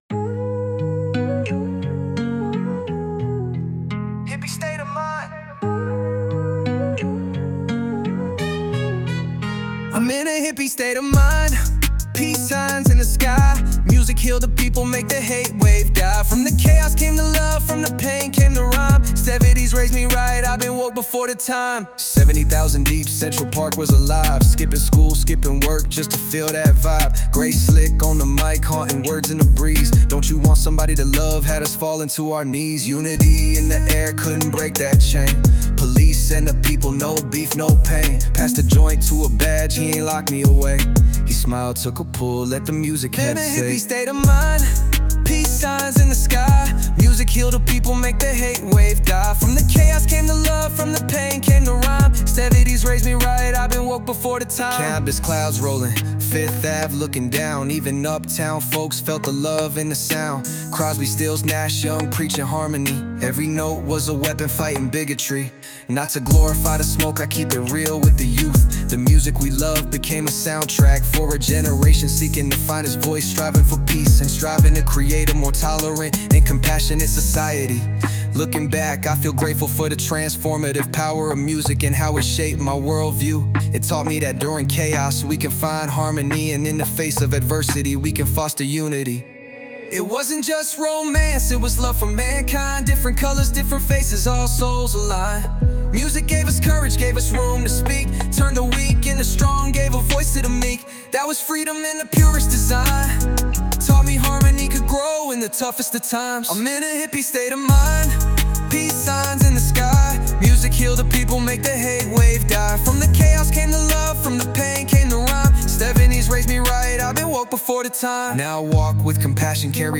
This Song was created by AI turning my Text to Lyrics and created this image for my book: There’s something out there